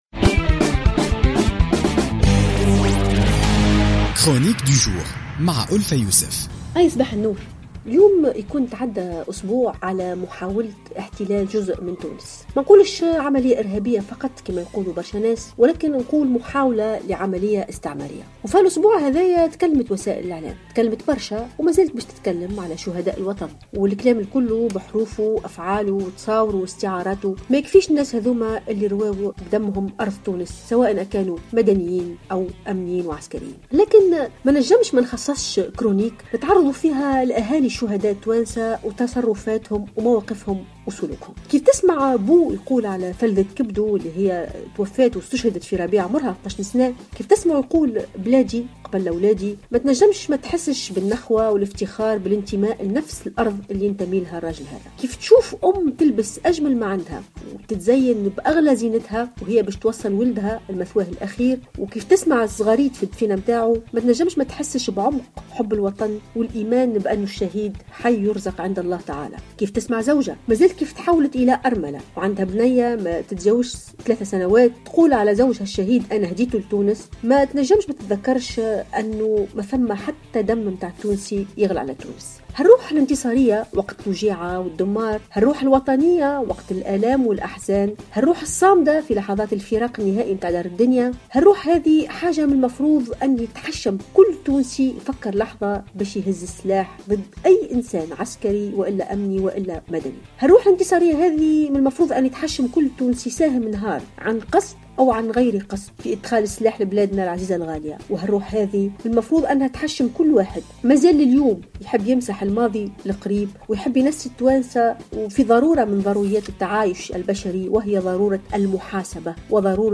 تحدثت الأستاذة والباحثة ألفة يوسف في افتتاحية اليوم الاثنين 14 مارس 2016 عن صمود وشموخ أهالي شهداء الوطن الذين فدوا تونس بدمائهم الزكية في ملحمة بنقردان التي خلدت أسماءهم في صفحات التاريخ بأحرف من ذهب.